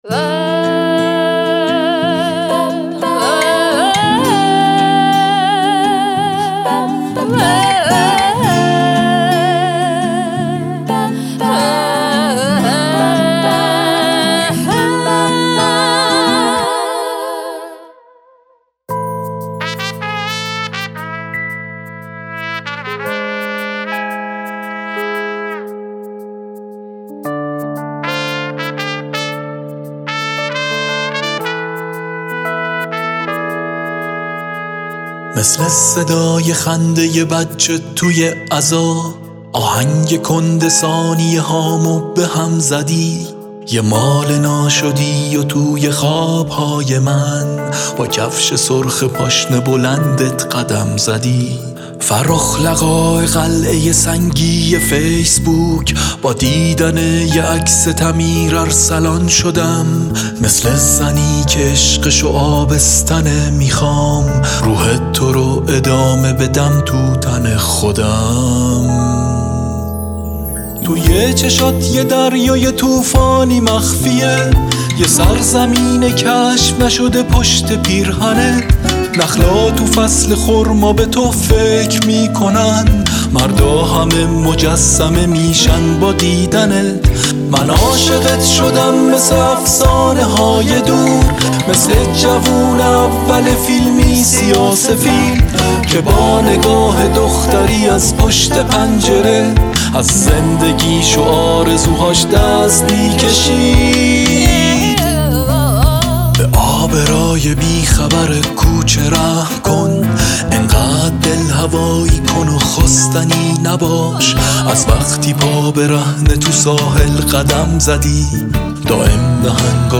اطلاعات دکلمه
گوینده :   [یغما گلرویی]